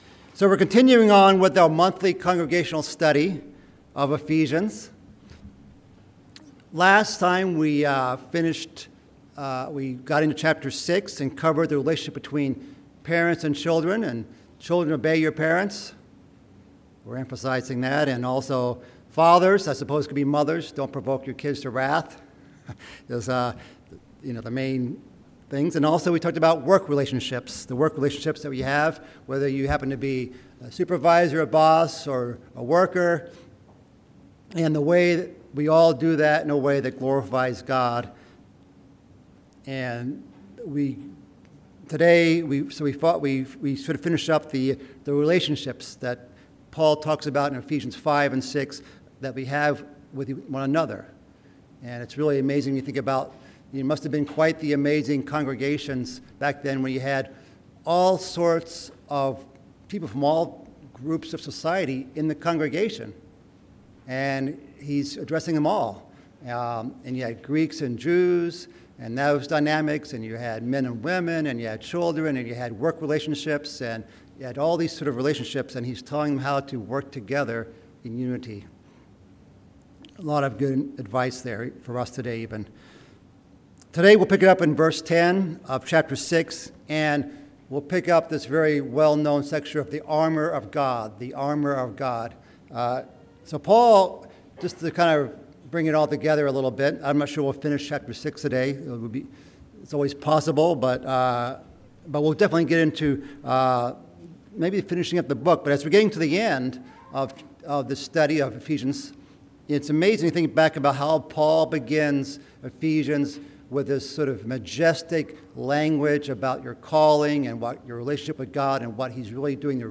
Bible Study: Ephesians